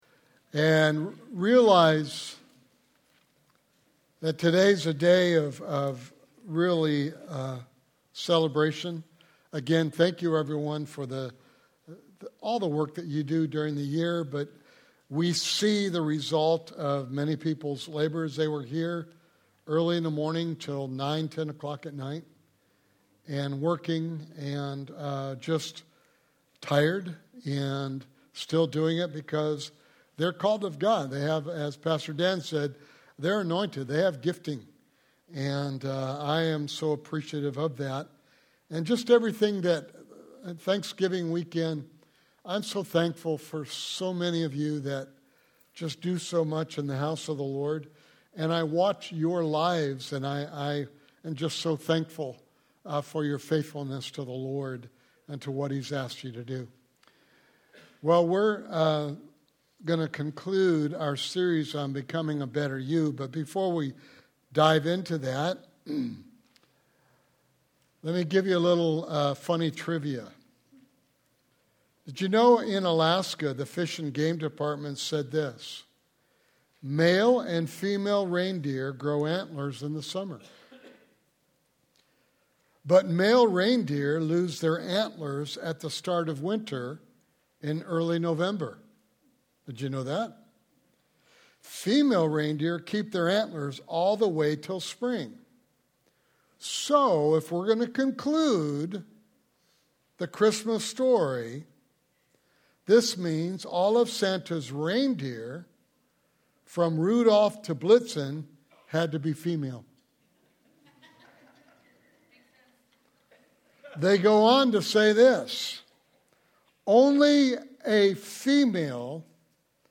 Sermon Series: Becoming the Better You